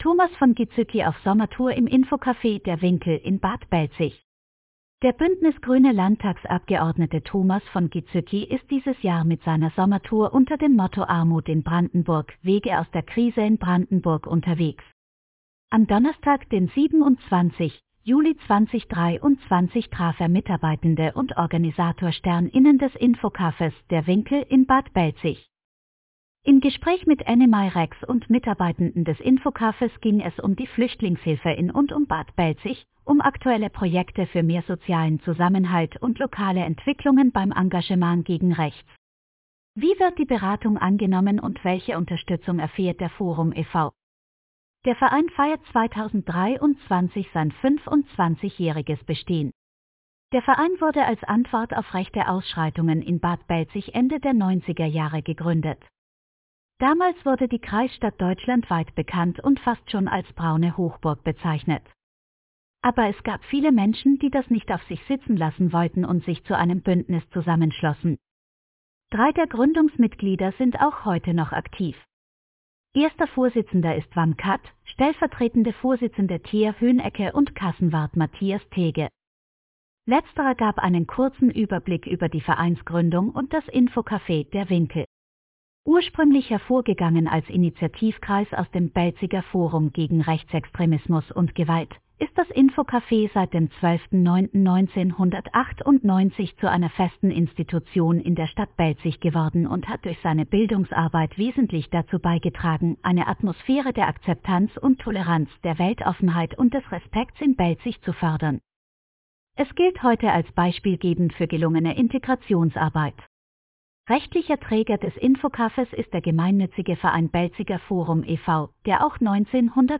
Ab sofort können Sie einige unserer Artikel auch hören. Eine KI macht es möglich.